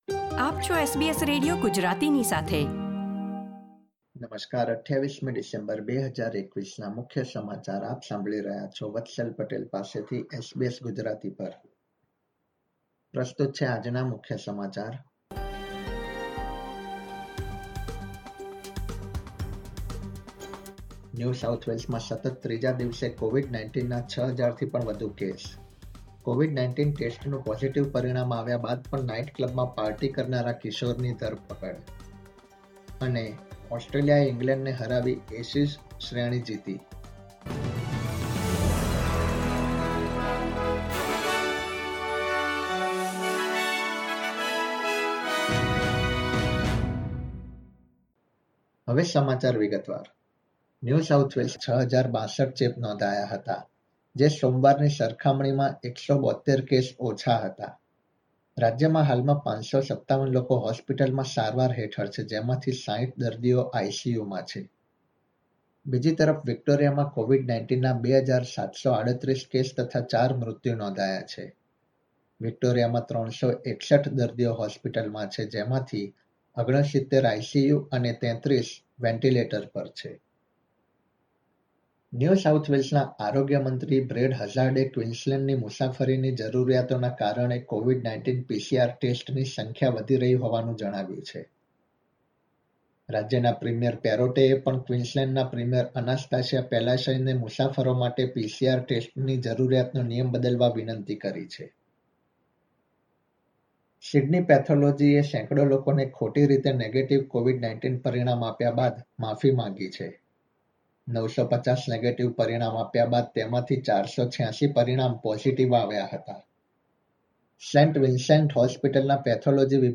SBS Gujarati News Bulletin 28 December 2021